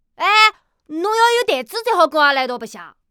c02_5小男孩_3.wav